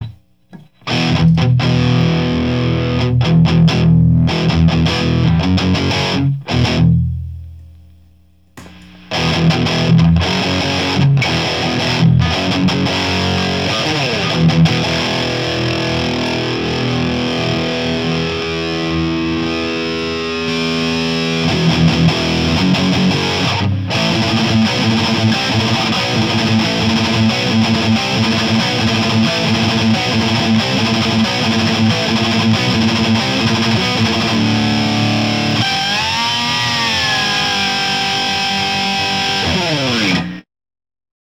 いつもながらお粗末なサンプルで。。。今回はEMG89です。
今回は、ゲイン5　トーンシフトオン
MTRはMRS-8を使いました。マイクはSM57
ザックドライブでのブースト。
ベリンガーは音をそのままブーストしてるだけなので、ザックドライブは歪みをプラスしているので音が違いますね！